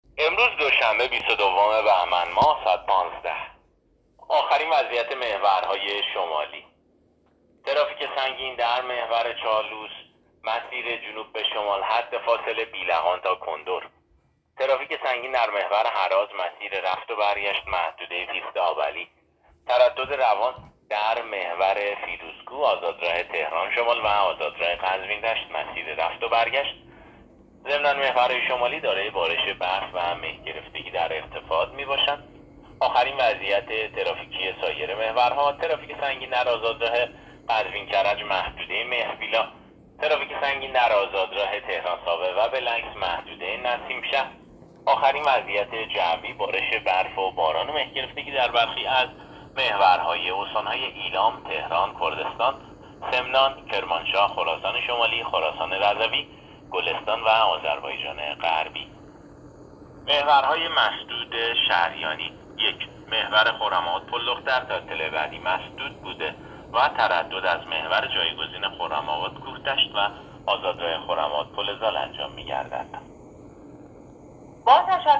گزارش رادیو اینترنتی از آخرین وضعیت ترافیکی جاده‌ها ساعت ۱۵ بیست و دوم بهمن؛